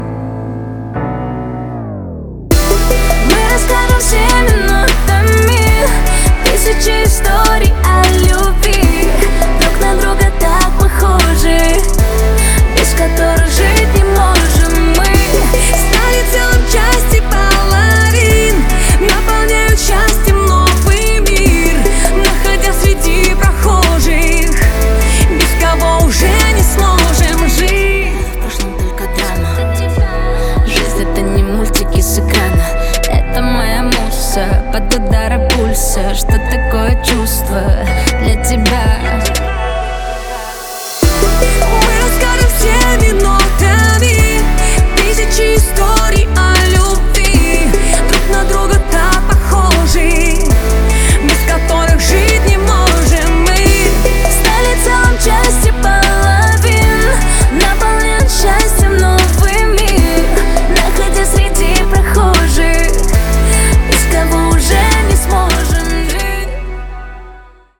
• Качество: 320, Stereo
поп
женский вокал
дуэт